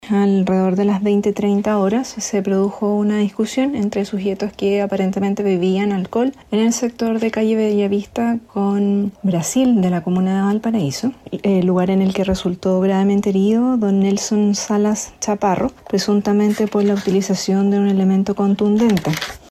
Por otra parte, la fiscal Javiera Torres se refirió a la sucesión de los hechos respecto al caso de homicidio en el sector de Bellavista.